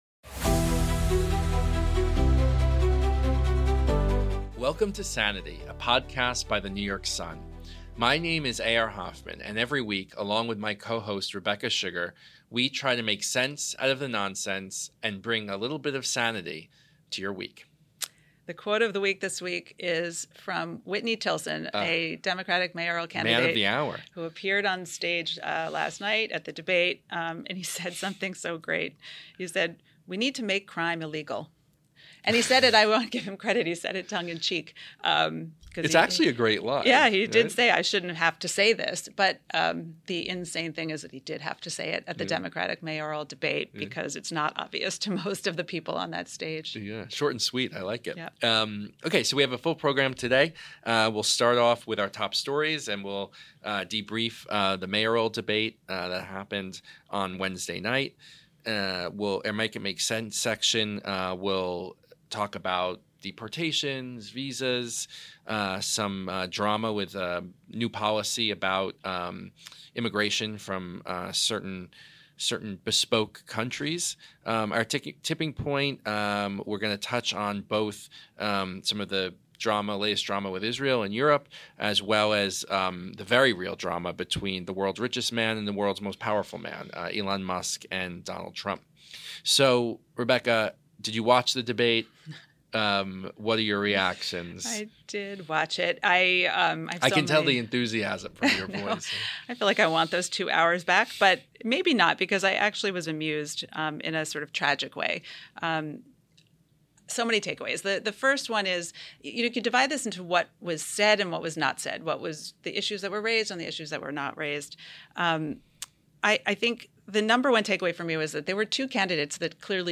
The Sanity Interview: Jonathan Greenert – Sanity by The New York Sun – Podcast
For this episode, we sat down with Admiral Jonathan Greenert, who served as the 30th Chief of Naval Operations. We talked about how to build more ships, the Red Sea, drones, AI, how things could play out in Taiwan, and what to do about China on the high seas.